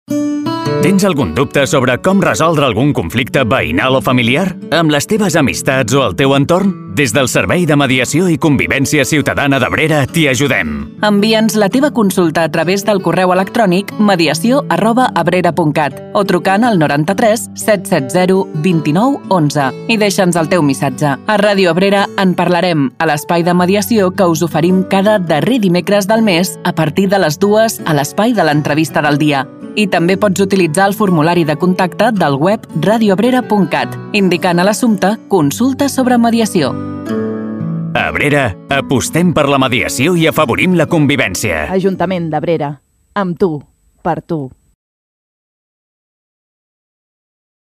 Escolta la falca de Ràdio Abrera de promoció del Servei de Mediació Ciutadana i Convència d'Abrera 01